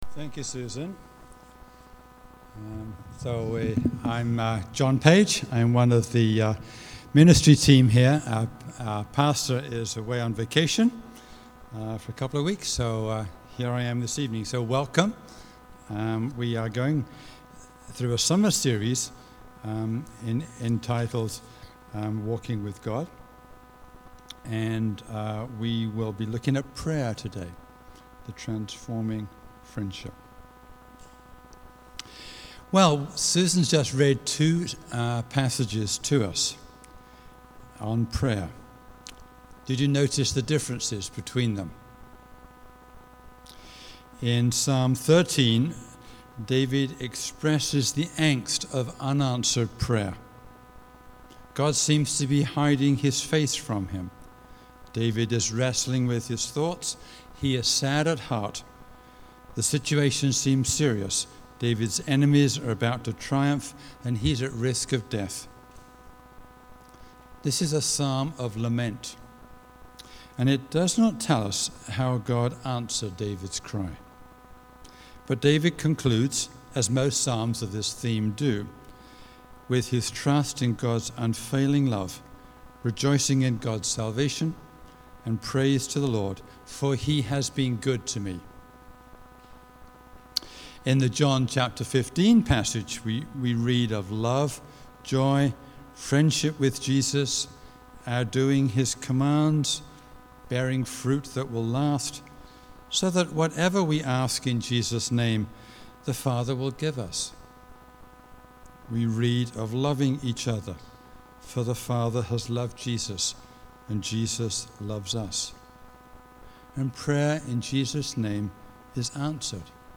Sermons | St. Thomas Mission